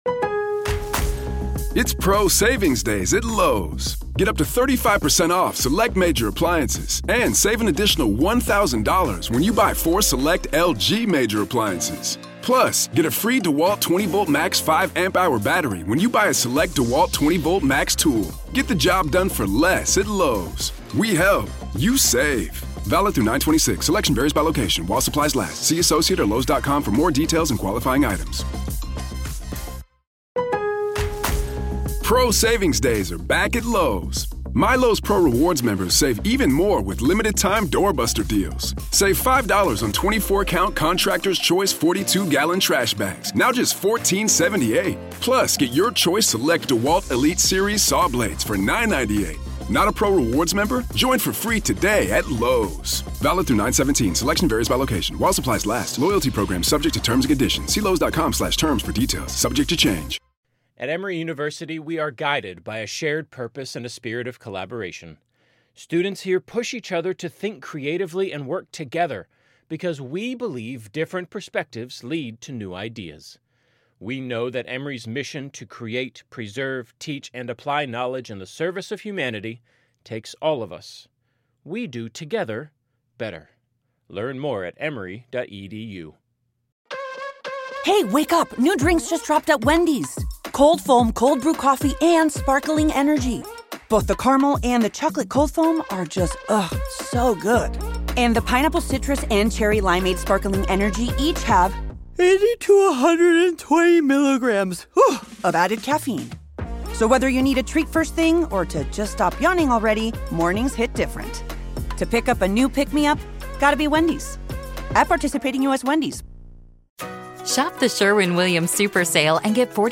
The hosts are two best friends who are chatting about true crime cases in the medical field.